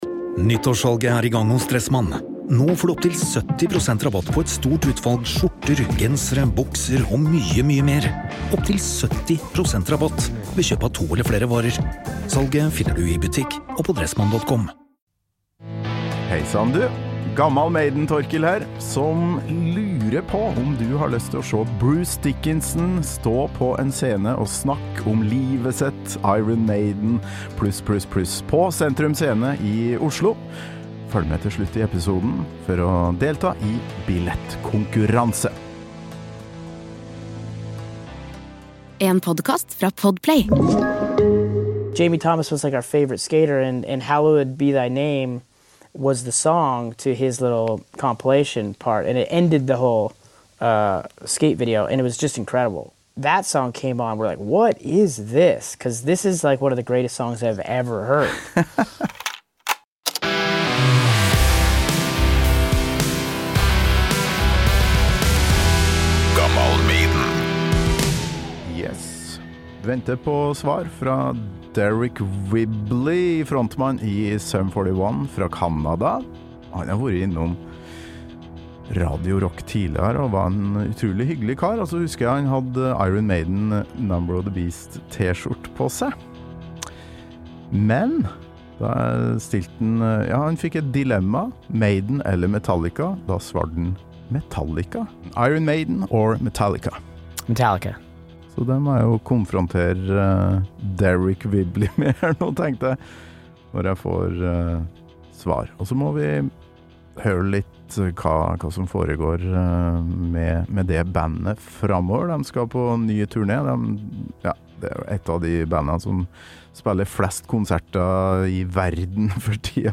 Sum 41-frontmann Deryck Whibley tar seg tid til en prat før Europa-turné.